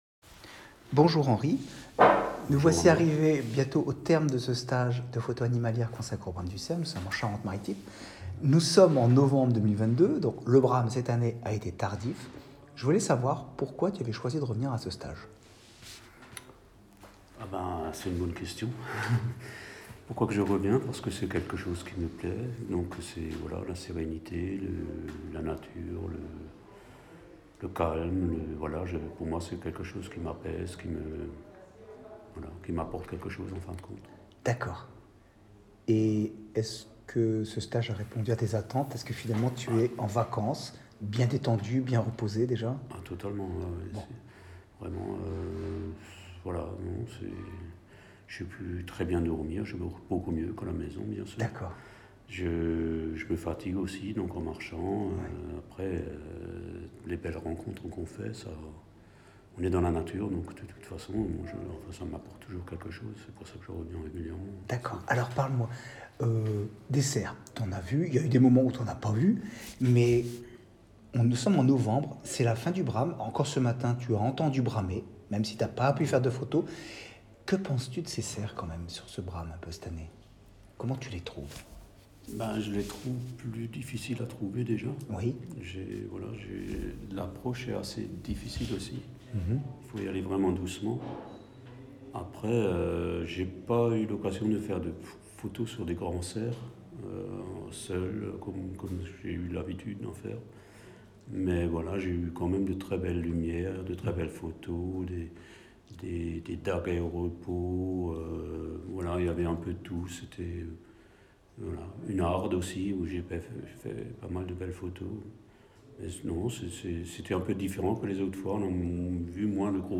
Le commentaire oral des participants